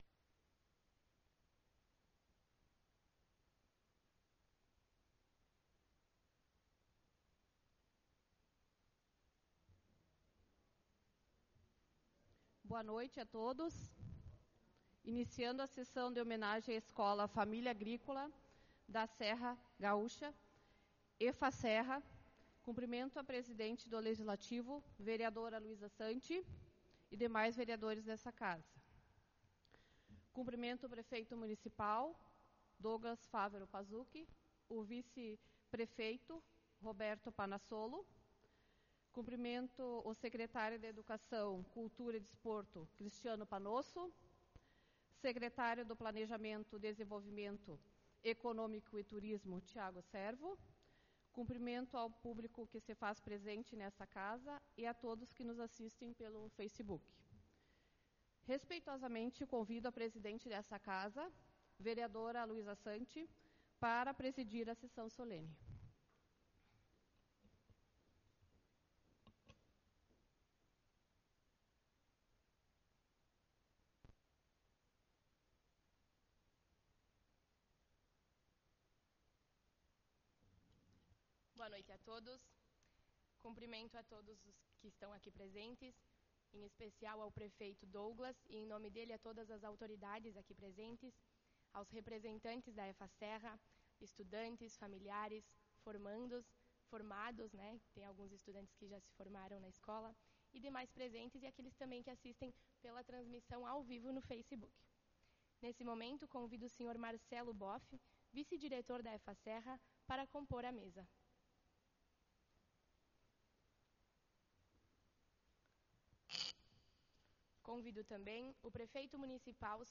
Sessão solene EFASERRA
Câmara de Vereadores de Nova Roma do Sul